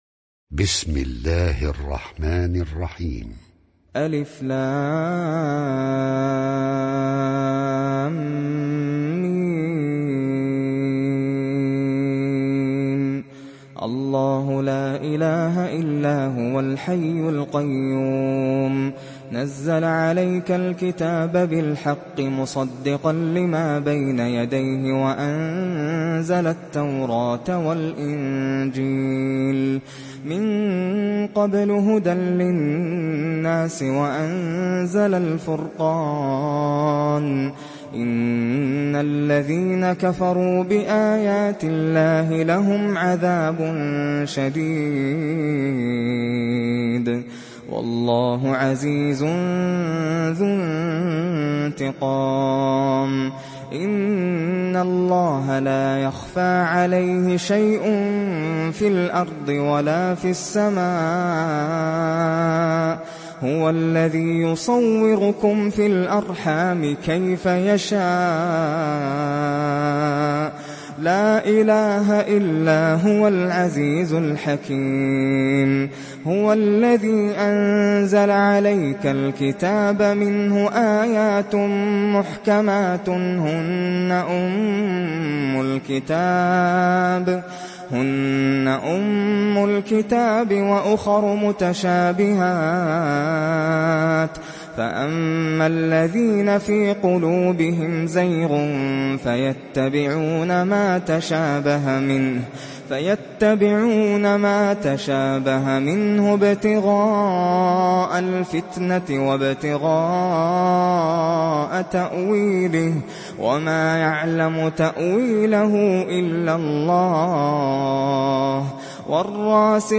تحميل سورة آل عمران mp3 بصوت ناصر القطامي برواية حفص عن عاصم, تحميل استماع القرآن الكريم على الجوال mp3 كاملا بروابط مباشرة وسريعة